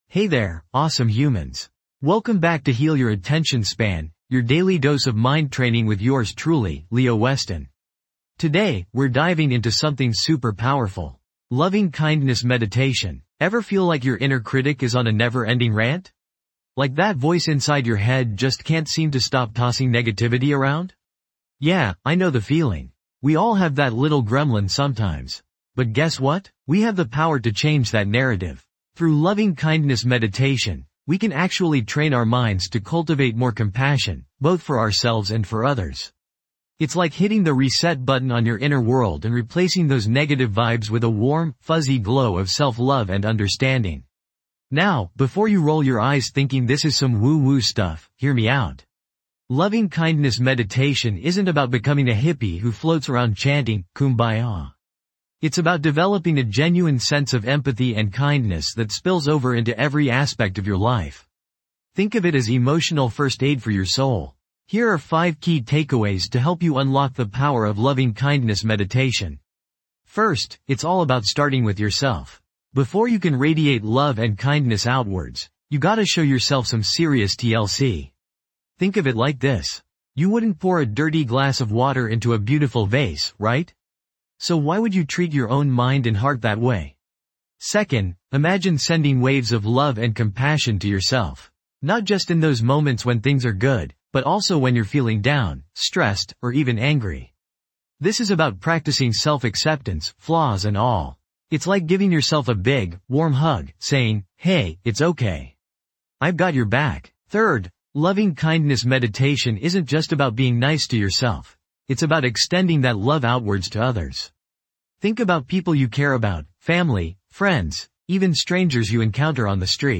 Episode Description:. Dive into a soothing practice of Loving Kindness Meditation designed to foster inner healing in this enlightening episode of Heal Your Attention Span Daily Mind Training.
This podcast is created with the help of advanced AI to deliver thoughtful affirmations and positive messages just for you.